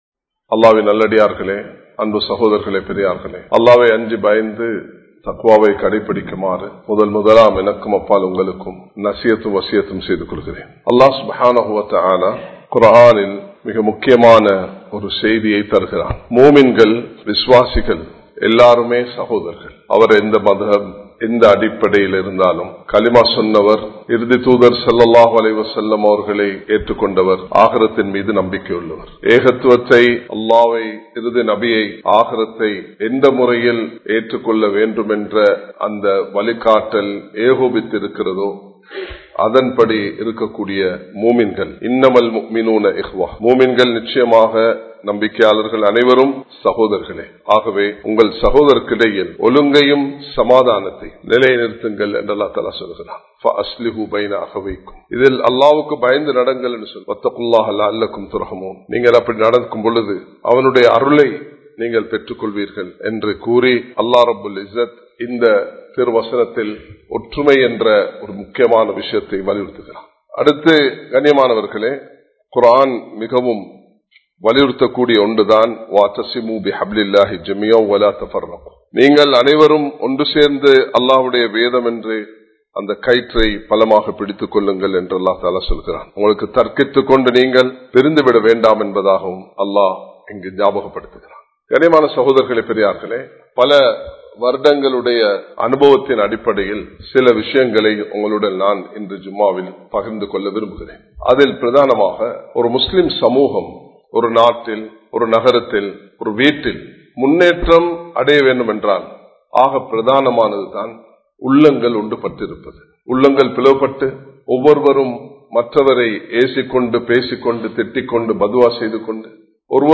உண்மையான முஸ்லிமாக வாழுங்கள் | Audio Bayans | All Ceylon Muslim Youth Community | Addalaichenai
Muhiyadeen Jumua Masjith